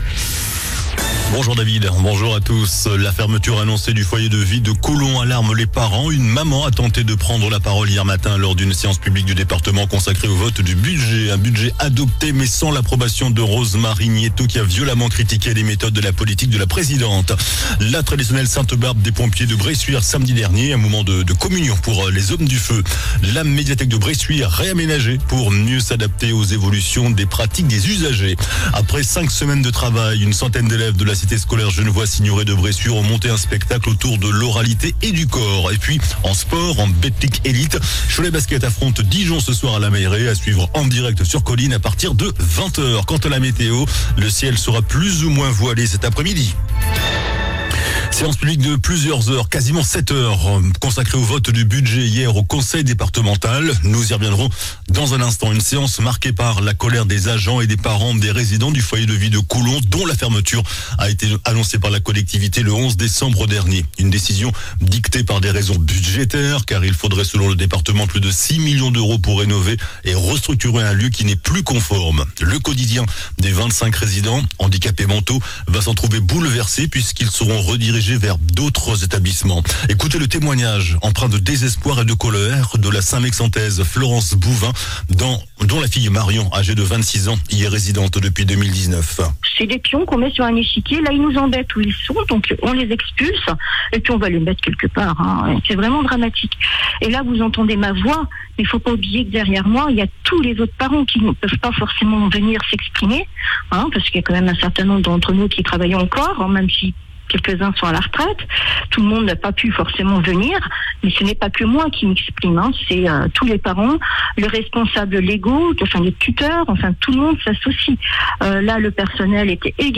JOURNAL DU MARDI 17 DECEMBRE ( MIDI )